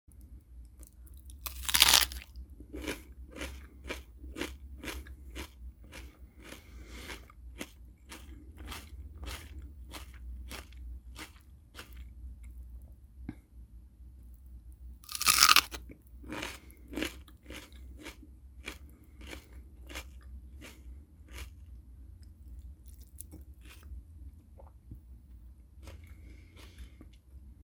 Откусывание капусты
Кусочек из нескольких листьев белокочанной капусты ест женщина.
2-varianta-otkusyvanija-kapusty.mp3